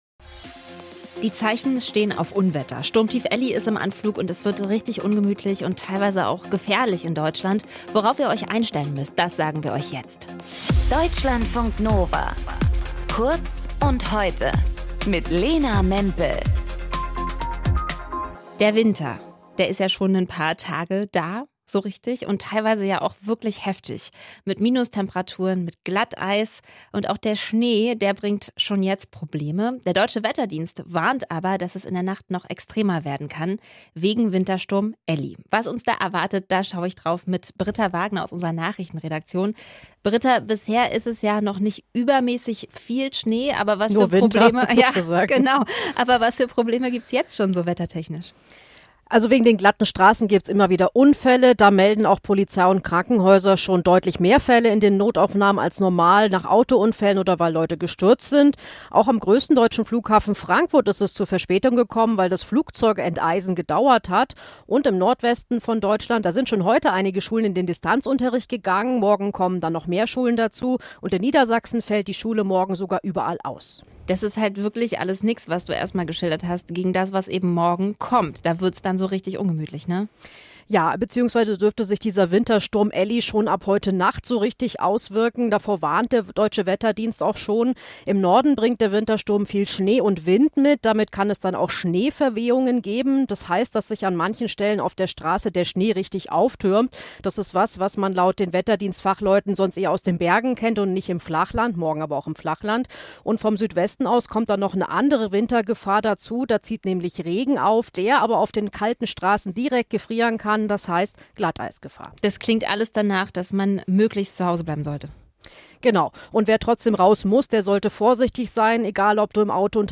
In dieser Folge mit:
Moderation:
Gesprächspartner: